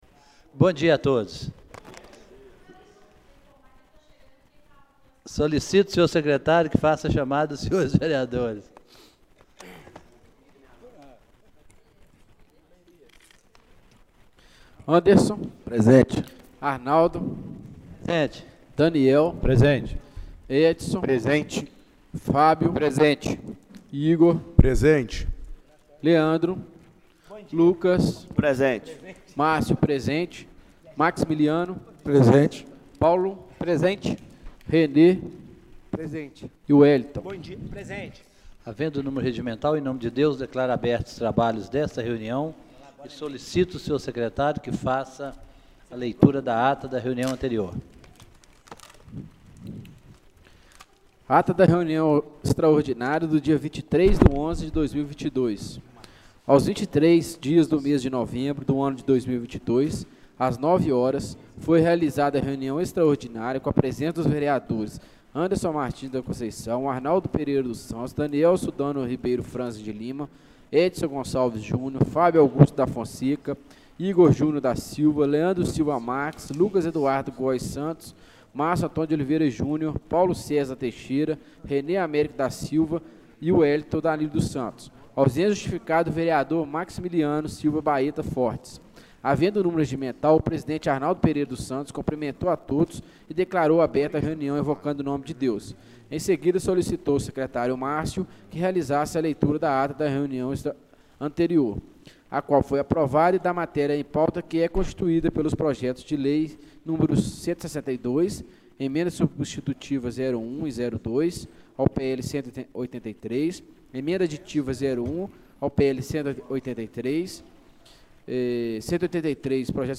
Reunião Extraordinária do dia 24/11/2022